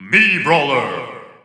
The announcer saying Mii Brawler's name in English and Japanese releases of Super Smash Bros. Ultimate.
Mii_Brawler_English_Announcer_SSBU.wav